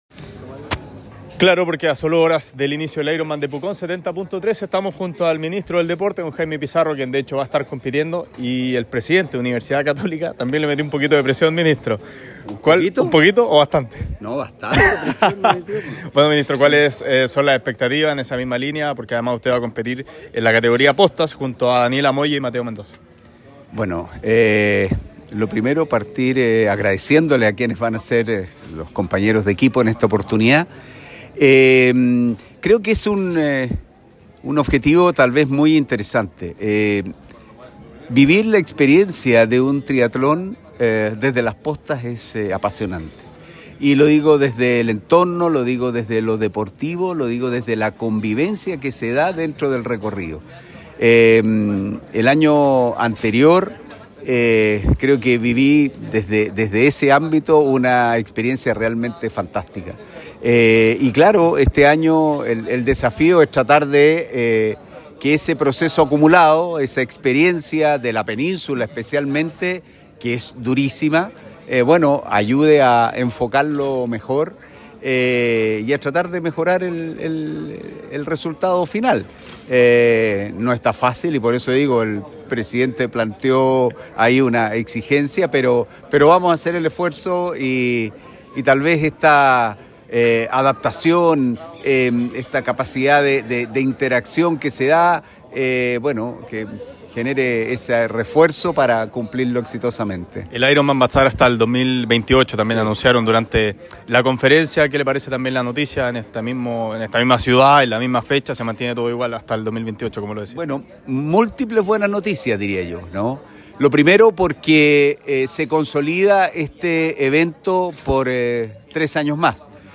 En conversación con ADN Deportes, el Ministro del Deporte, Jaime Pizarro, se refirió a la salida de su hijo de Colo Colo y su llegada a Rosario Central.